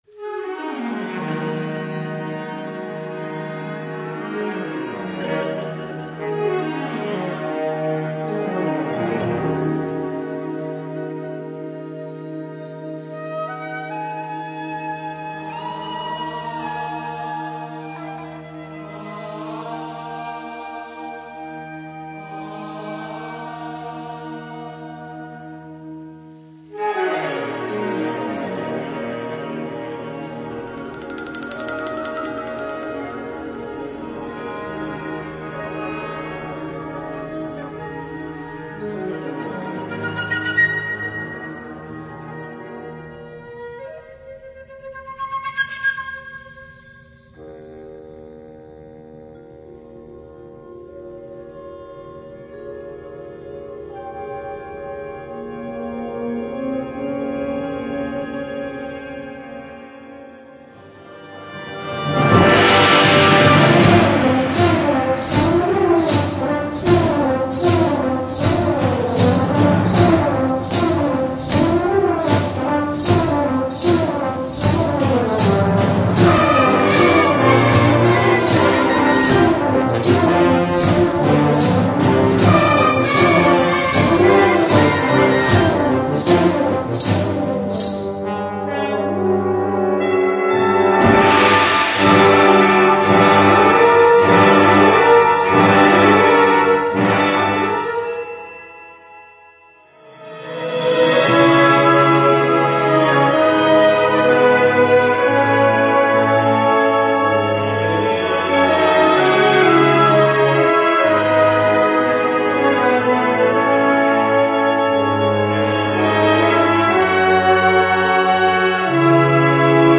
Gattung: Konzertwerk
Besetzung: Blasorchester